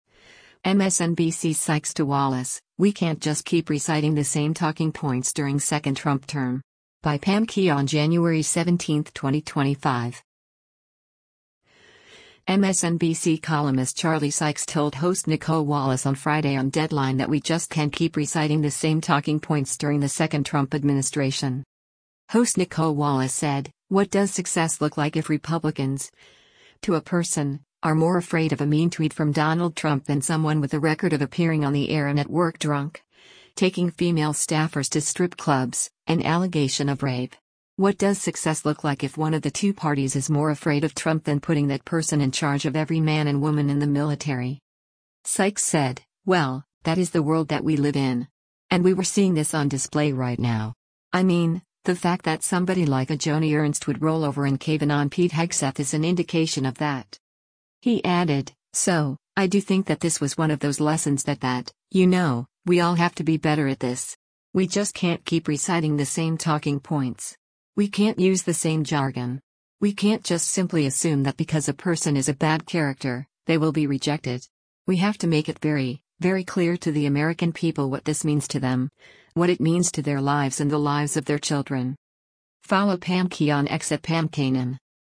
MSNBC columnist Charlie Sykes told host Nicolle Wallace on Friday on “Deadline” that “we just can’t keep reciting the same talking points” during the second Trump administration.